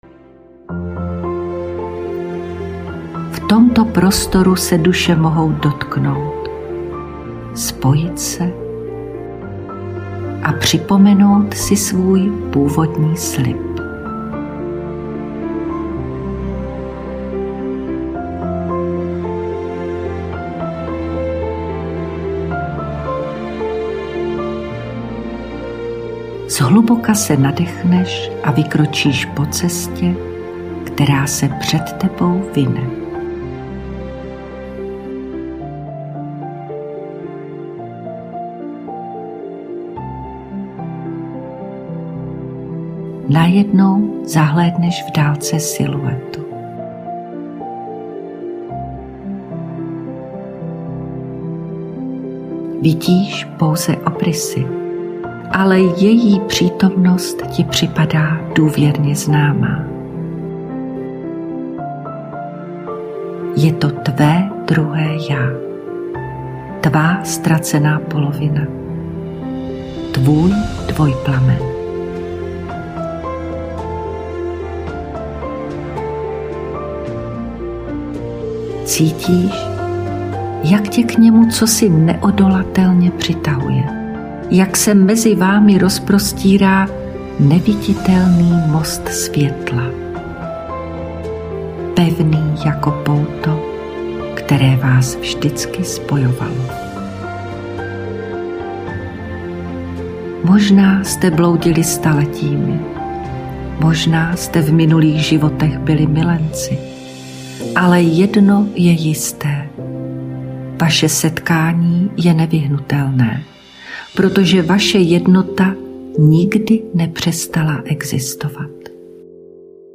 Vedená vizualizace pro spojení s dvojplamenem je hluboká meditace, která tě provede procesem energetického sladění se svým dvojplamenem.
Dvojplamen: Ukázka z meditace (celková délka 15: 55)